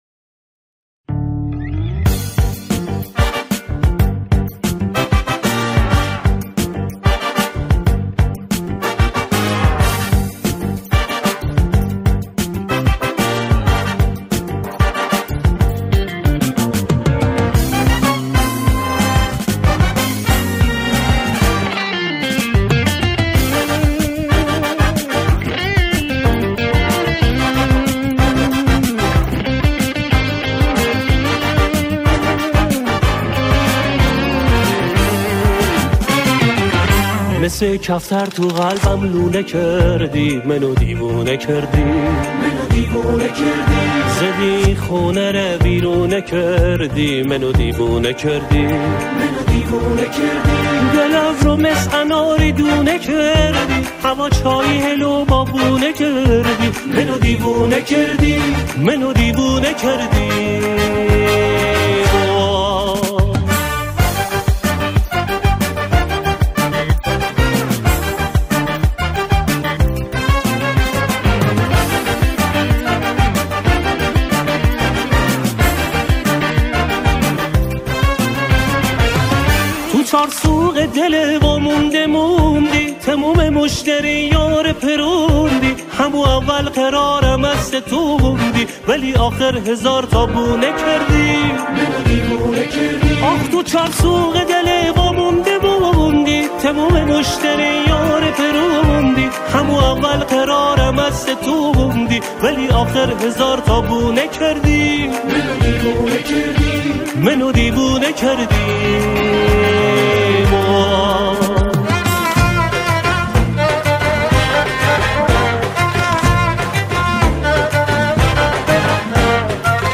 شعر این سرود به گویش کرمانی سروده شده است.